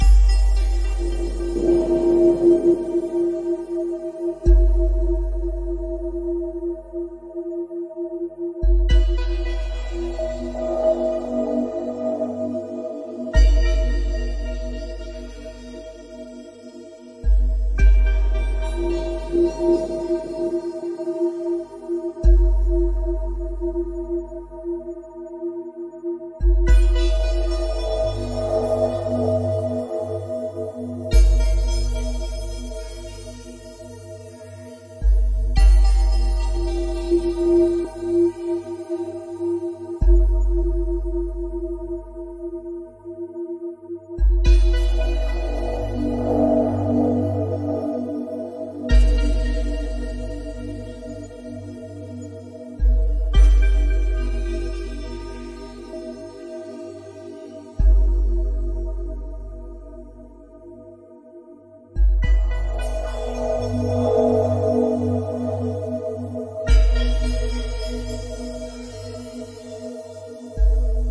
描述：缓慢而深刻的科幻氛围配乐。无缝循环，每分钟108次。
Tag: 处理时 背景声 声景 氛围 合成器 ATMO ATMOS 黑暗 电影 反物质 空间 科幻 气氛 音带